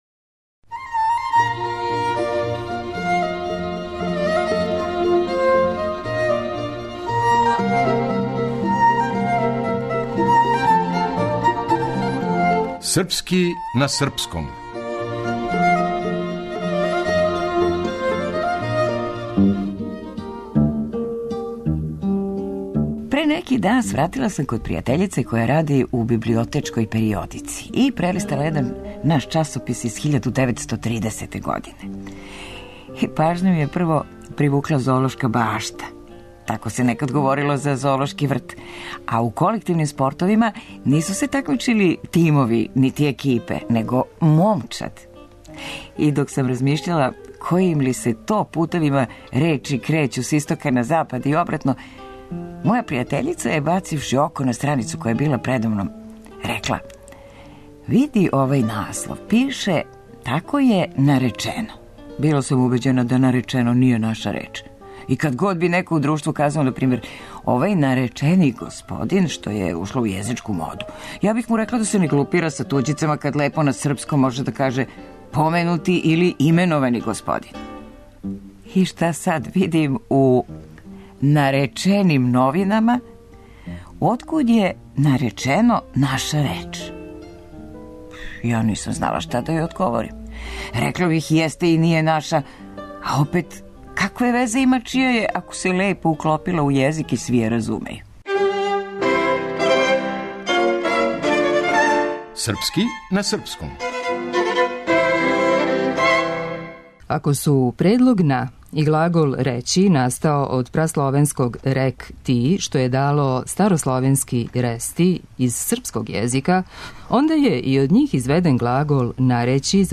Драмски уметник
Уредник и водитељ